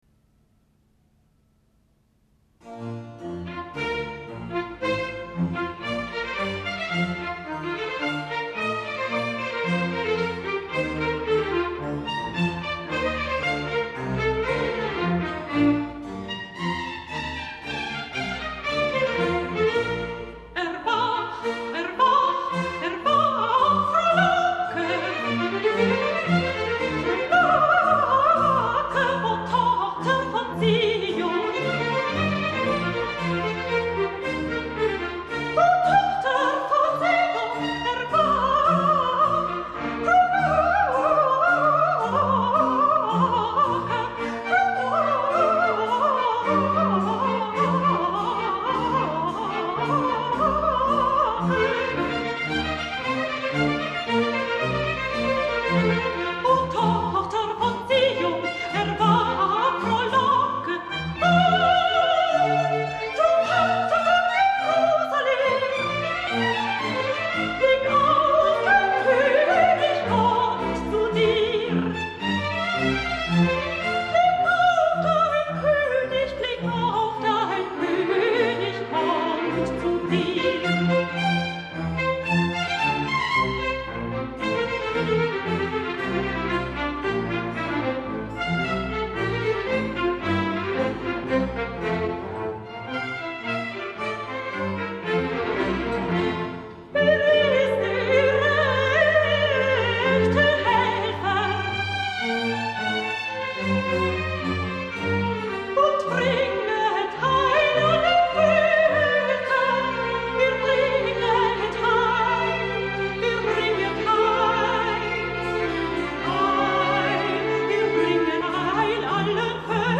Aria per Soprano
(versione ritmica in tedesco)
Soprano